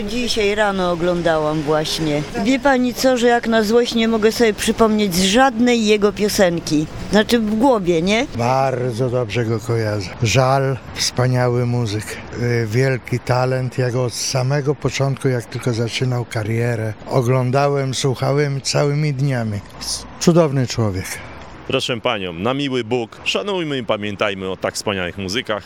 Zapytaliśmy mieszkańców Stargardu jak będą go wspominać.
sonda.mp3